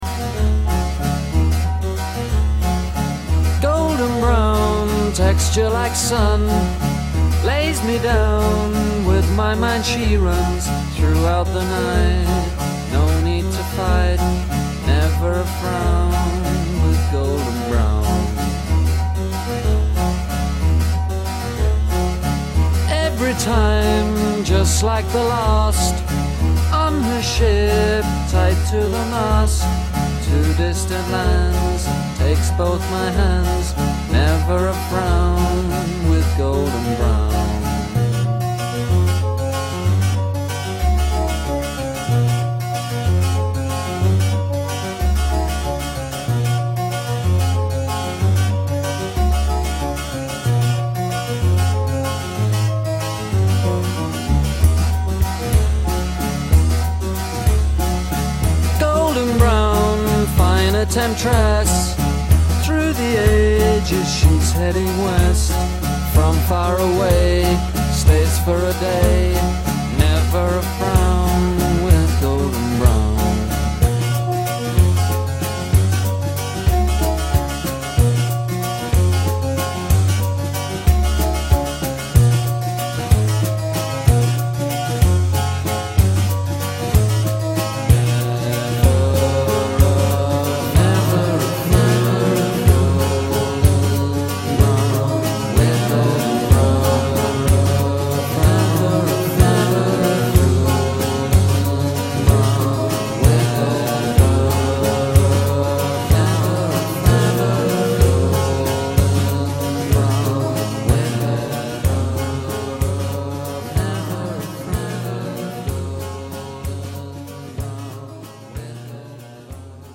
MP3 / Korg / Guitare / Flute er Chant / Paroles